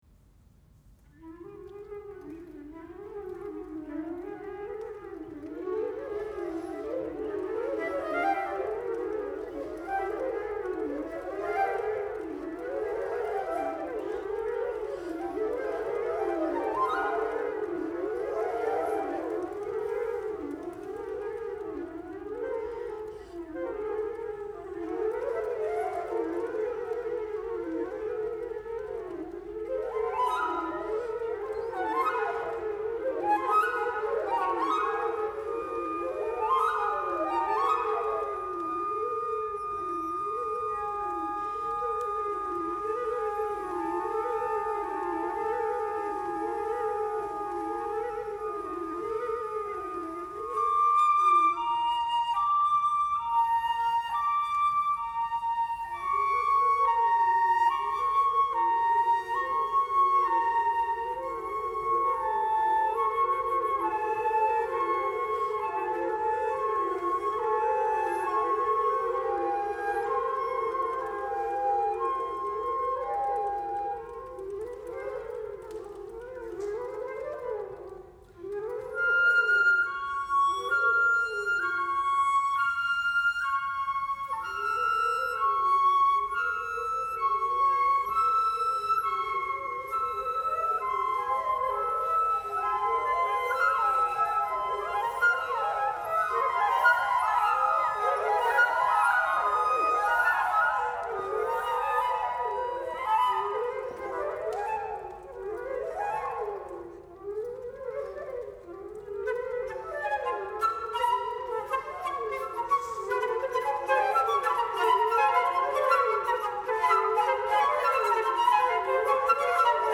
5:00. 8 flutes.
for eight flutes